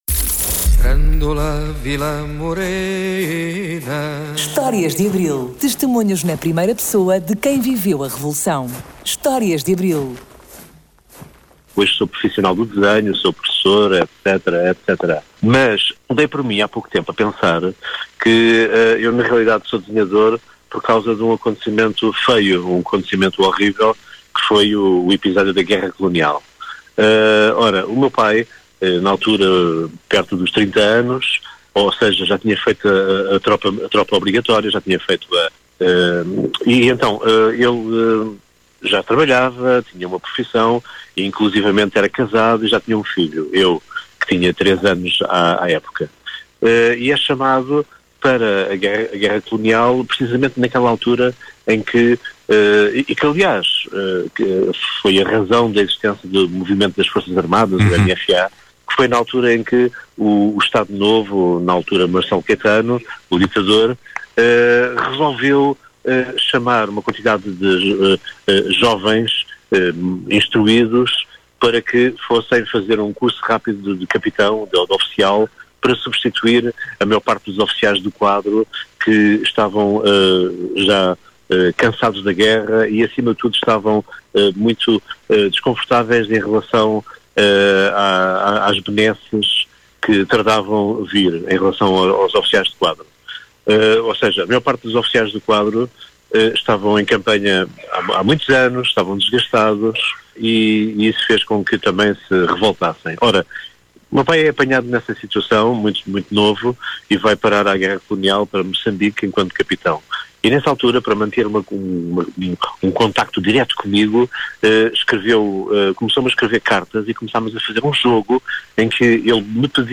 Testemunhos na primeira pessoa de quem viveu o 25 de Abril de 1974.